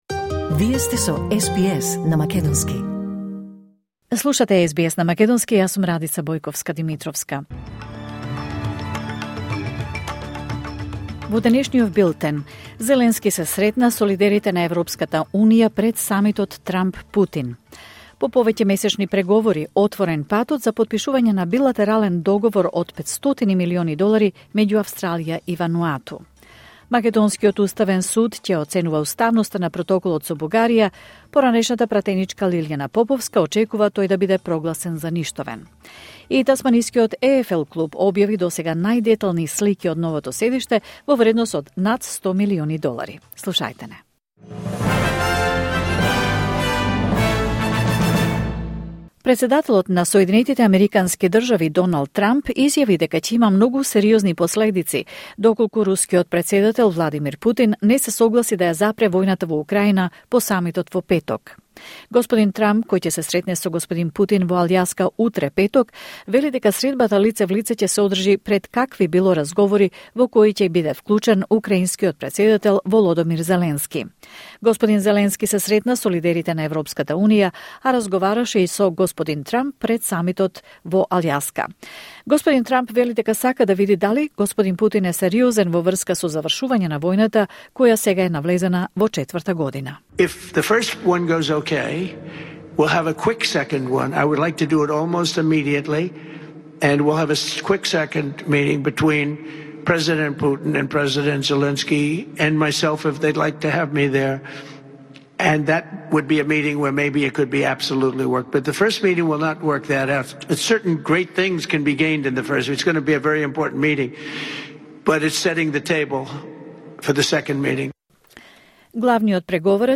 Вести на СБС на македонски 14 август 2025